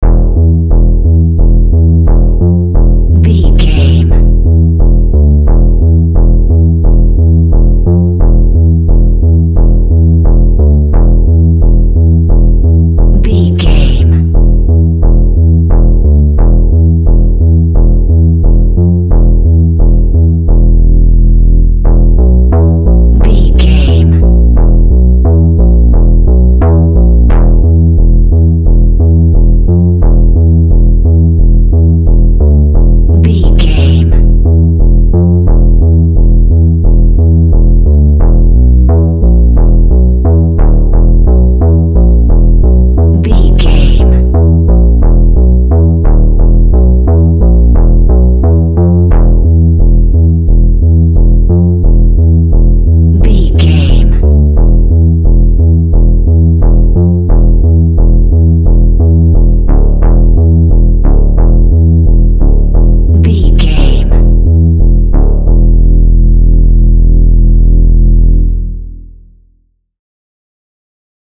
Thriller
Aeolian/Minor
DOES THIS CLIP CONTAINS LYRICS OR HUMAN VOICE?
Slow
tension
ominous
dark
suspense
haunting
eerie
synthesiser
Horror synth
Horror Ambience
electronics